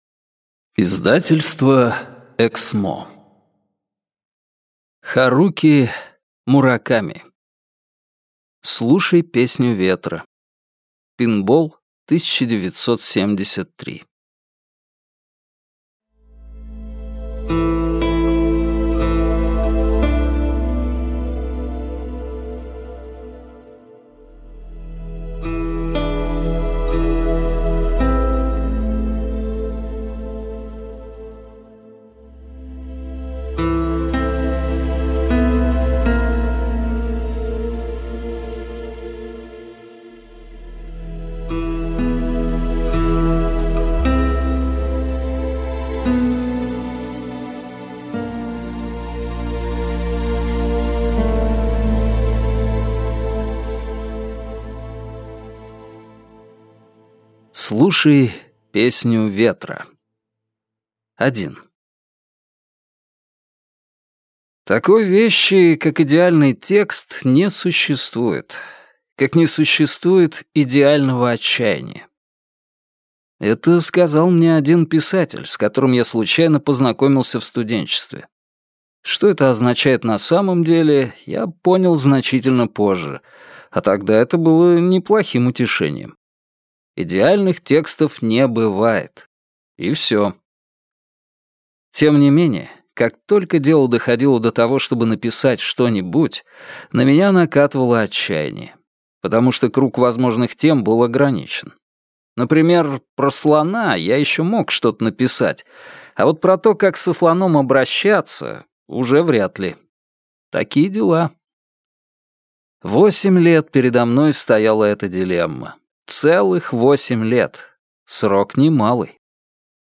Аудиокнига Слушай песню ветра. Пинбол 1973 (сборник) | Библиотека аудиокниг